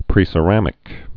(prēsə-rămĭk)